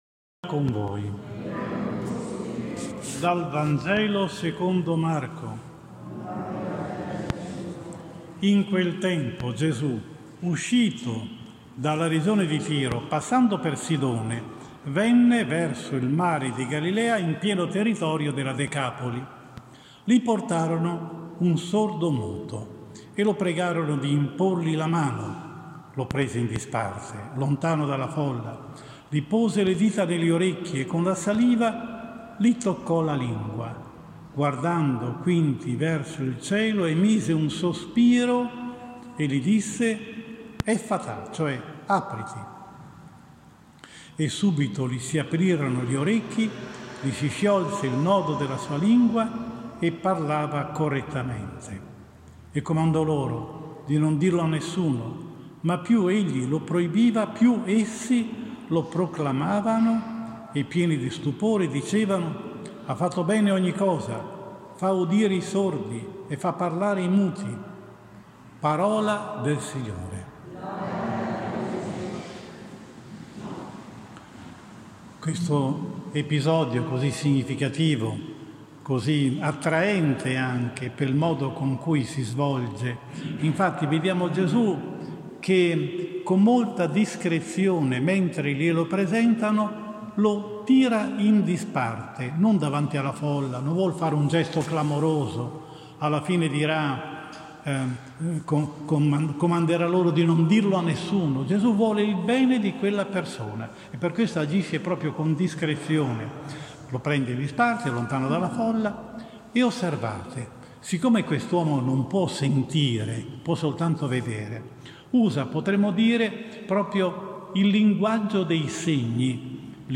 5 Settembre 2021 XXIII DOMENICA Tempo ordinario, anno B – omelia